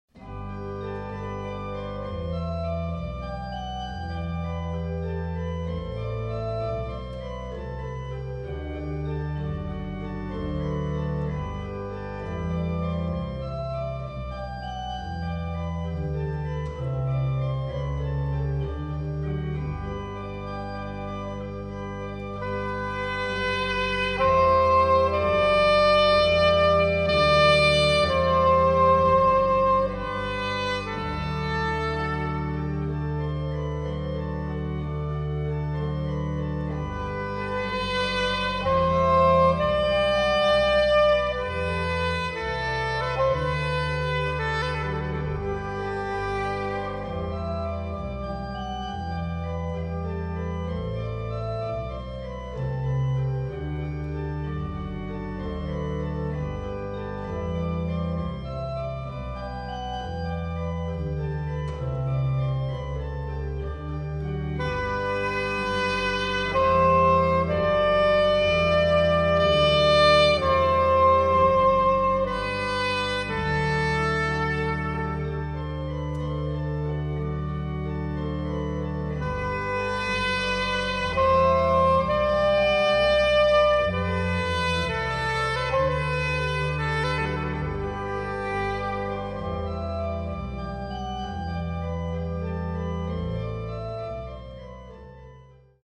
Orgel
Trompete
Querflöte, Sopransax
Violine